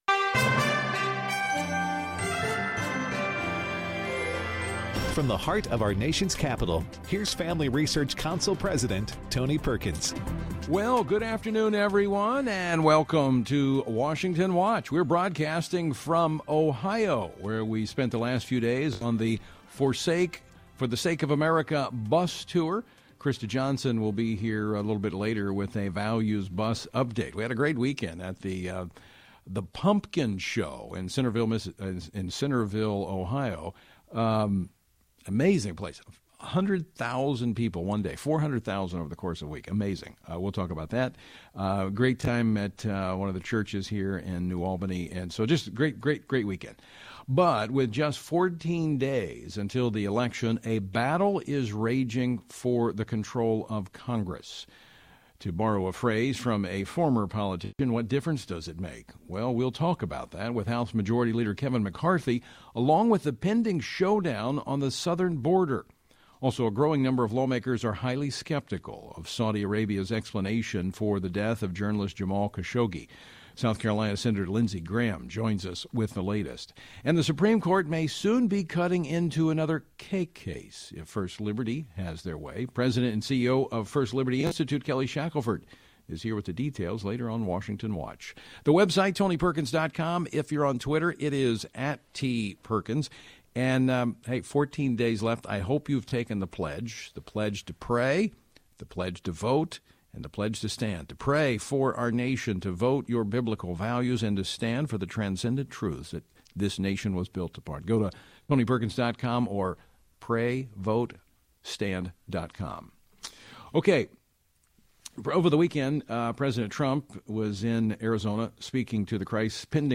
Also, Senate Judiciary Committee member Sen. Lindsey Graham (R-S.C.) joins Tony to weigh in on the growing skepticism among lawmakers of Saudi Arabia’s explanation for the death of journalist Jamal Khashoggi.